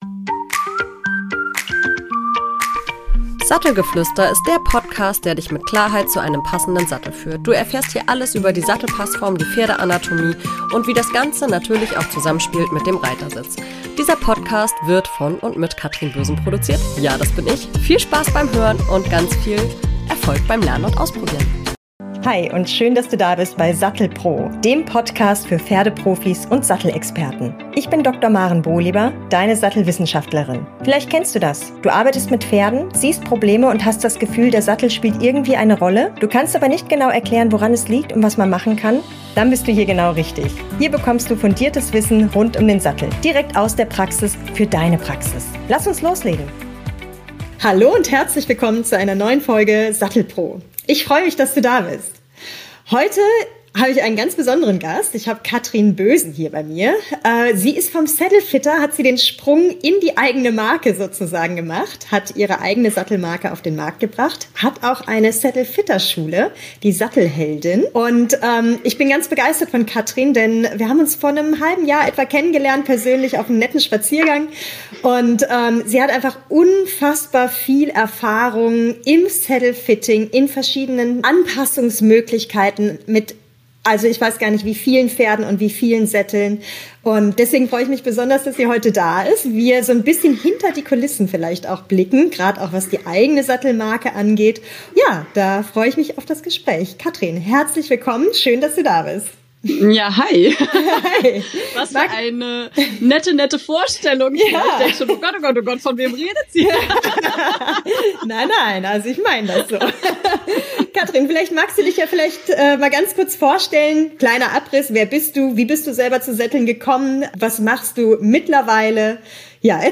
Ein ehrliches, tiefgründiges Gespräch über Haltung, Herausforderungen und die Leidenschaft für Pferde, Reiter und Sättel.